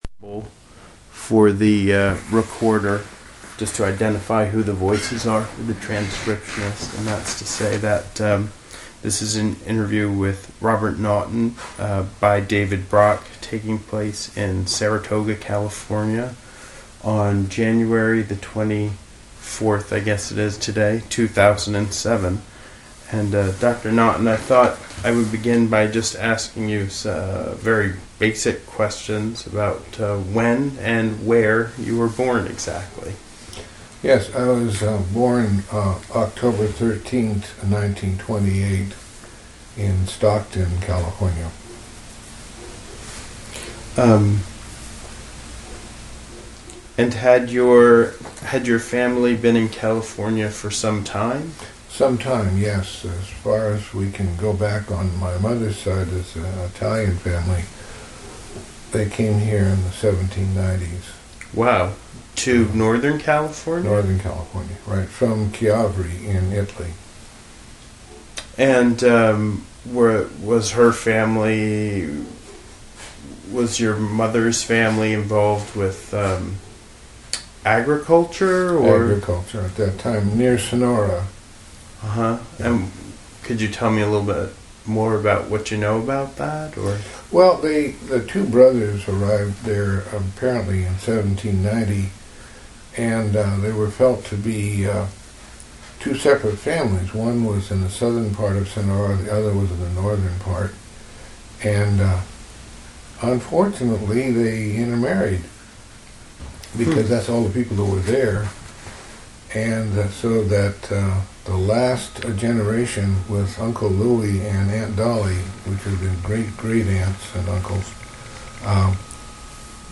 Genre Oral histories